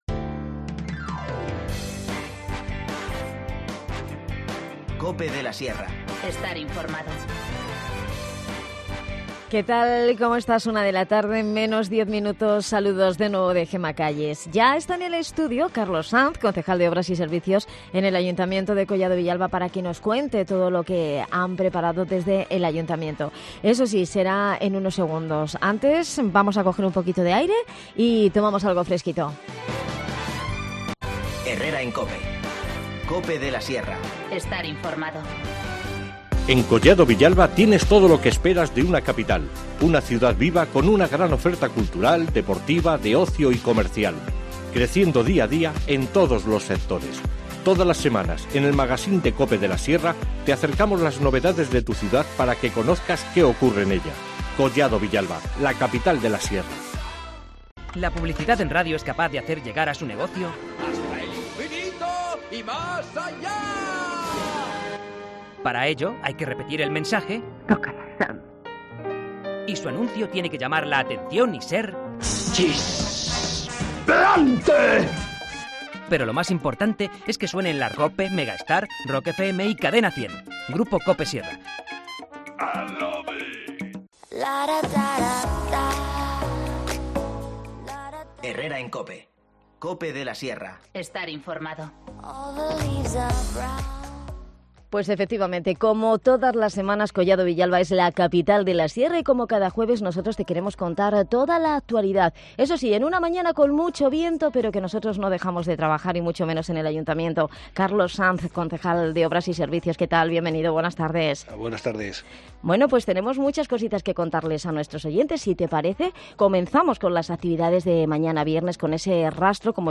AUDIO: Recibimos a Carlos Sanz, concejal de Obras y Servicios en el ayuntamiento de Collado Villalba, para repasar toda la agenda de cultura